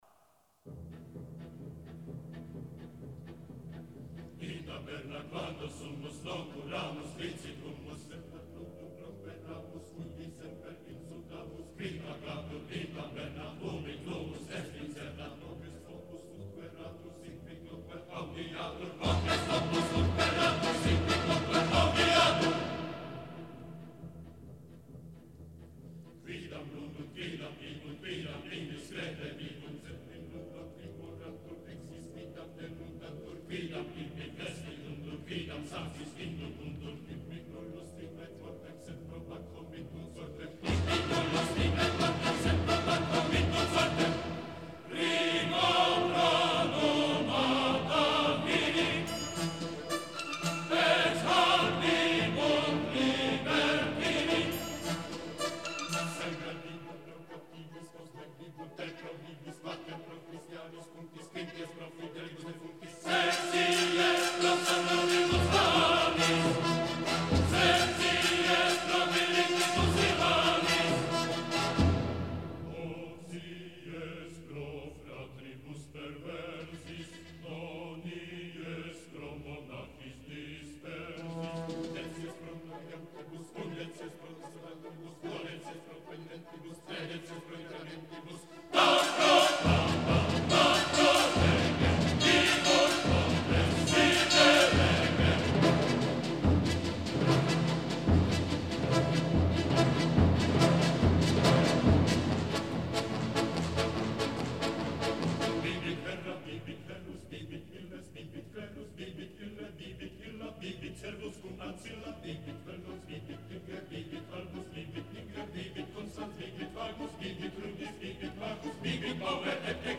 Deixo-vos com uma interpretação da peça e o texto da versão latina cantada.